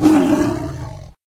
combat / creatures / tiger / he / attack1.ogg